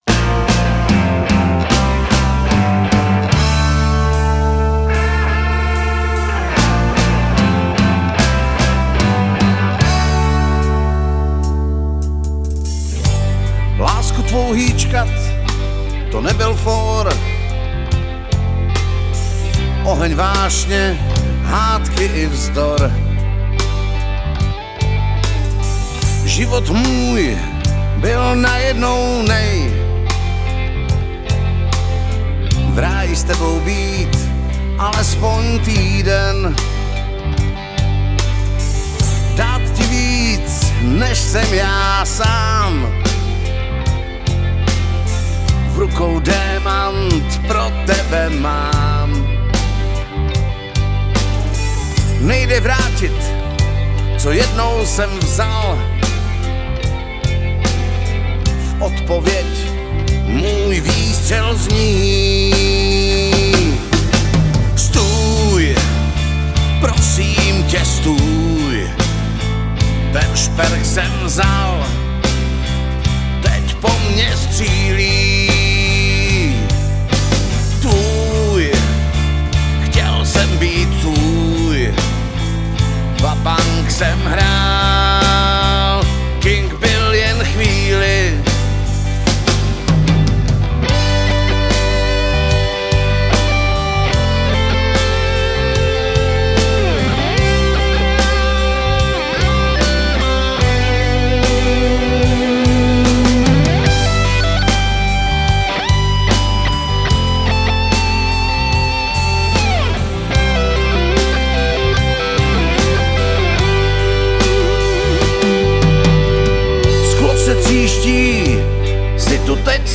romantická balada